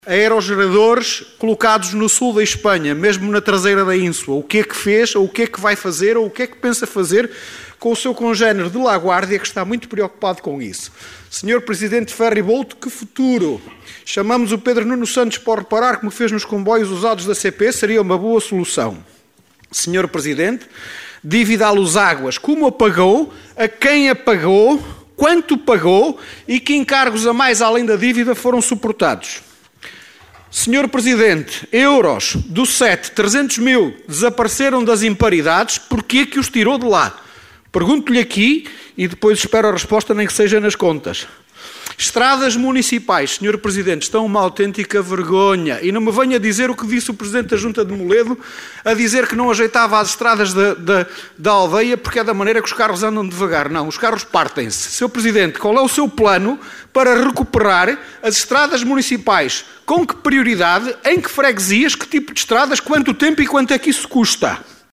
O parque eólico que está projetado para o sul da Galiza, a dívida à Luságua, a questão do CET, o Ferry Boat e o estado “vergonhoso”  em que se encontram as ruas e estradas municipais foram algumas das questões deixadas pelo deputado da coligação O Concelho em Primeiro (OCP) Jorge Nande ao Presidente da Câmara Rui Lages no período antes da ordem do dia da última assembleia municipal de Caminha.